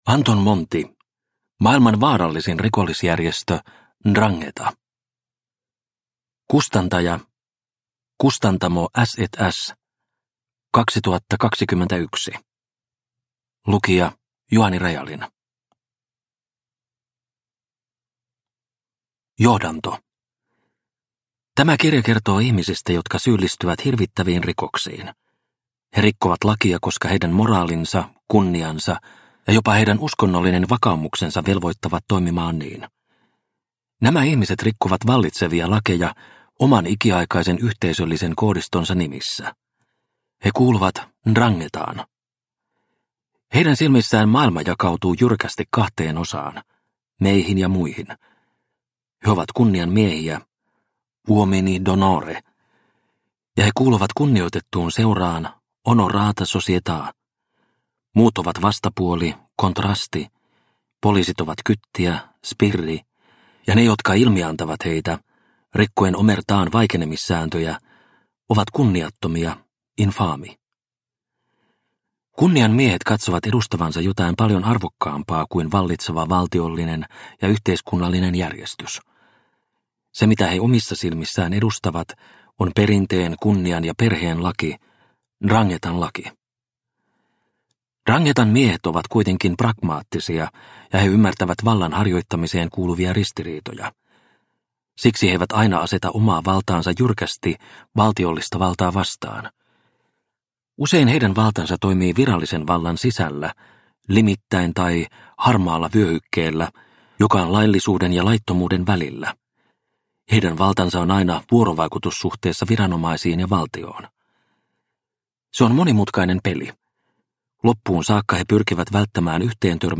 Maailman vaarallisin rikollisjärjestö 'Ndrangheta – Ljudbok – Laddas ner